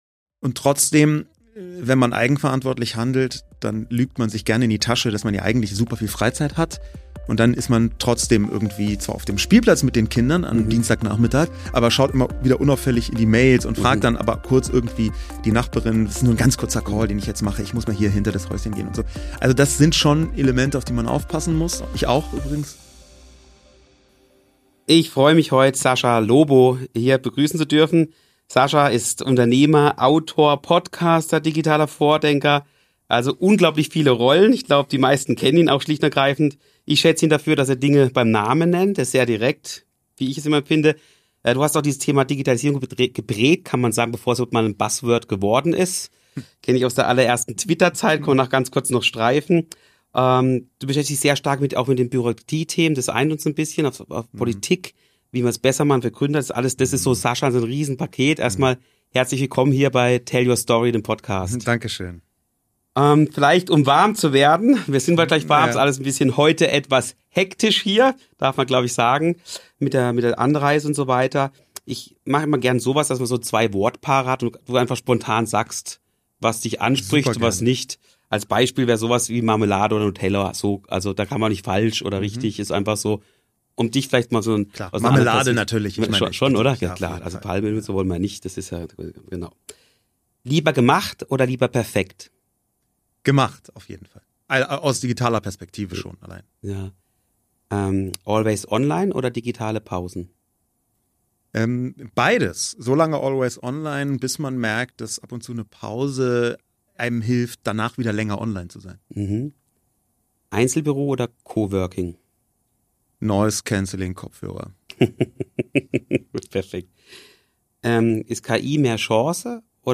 Ein inspirierendes Gespräch über Mut, Fehlerkultur und die Möglichkeiten von KI.